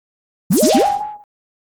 Petscop11-NeedlesPianoAppear.ogg